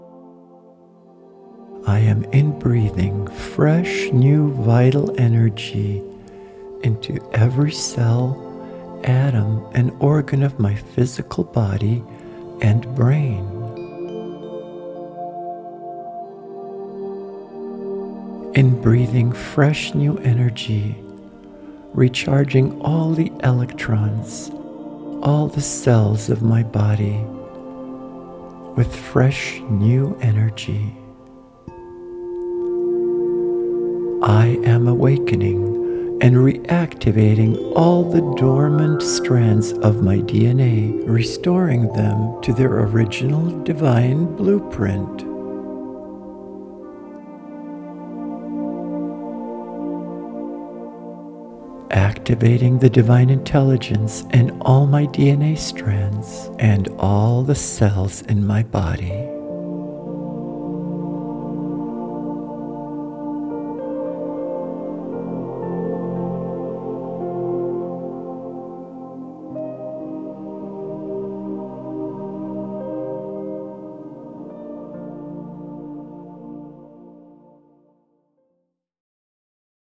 Immerse yourself in a dynamic 15 minute quantum guided meditation and affirmations to empower you daily and align with your Higher Self.